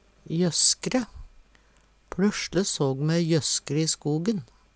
jøsskre - Numedalsmål (en-US)